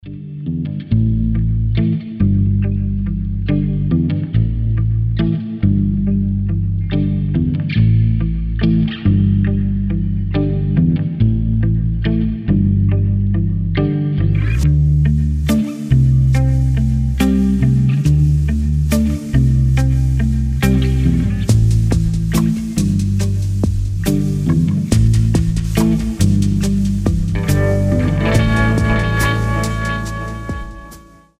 инструментальные
блюз , из сериалов , гитара , без слов